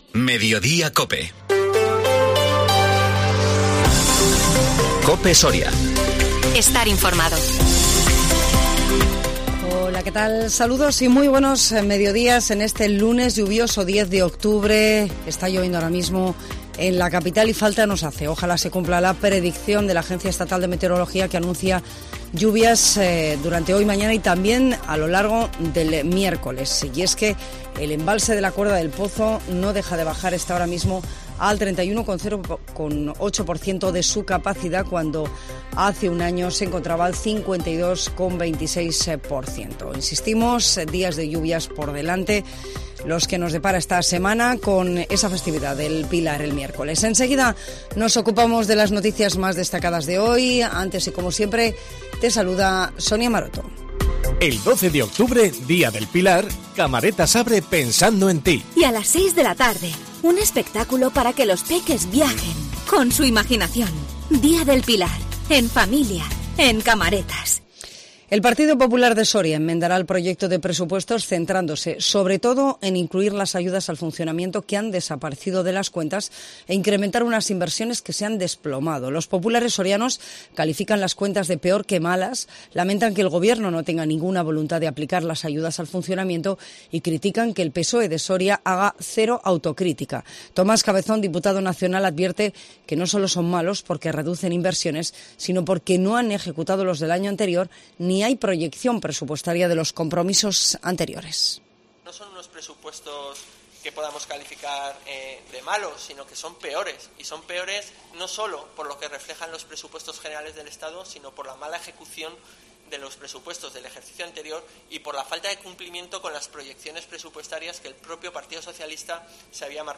INFORMATIVO MEDIODÍA COPE SORIA 10 OCTUBRE 2022